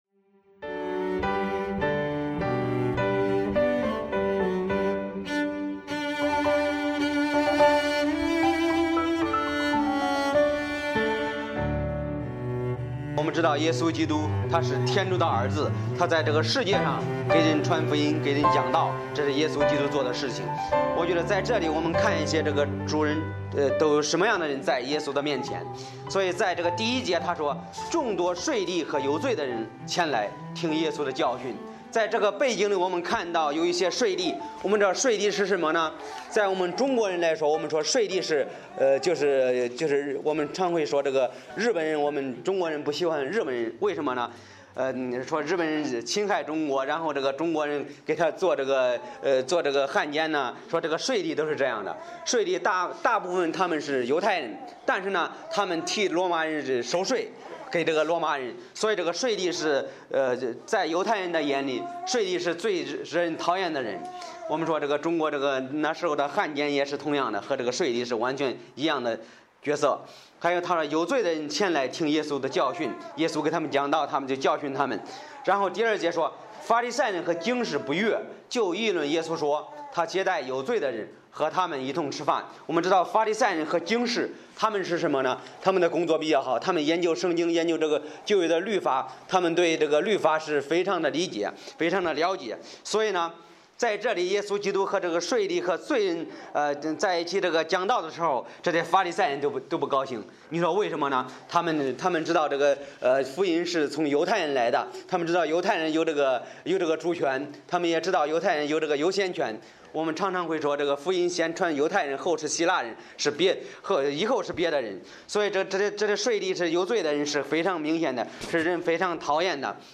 Bible Text: 路加福音15：1-32 | 讲道者